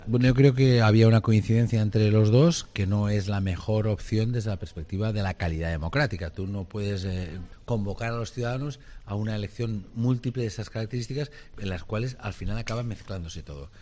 Además, en una entrevista en COPE Valencia, Puig ha asegurado que coincidió con Sánchez en que un ‘superdomingo’, “no es la mejor opción desde la perspectiva de la calidad democrática”.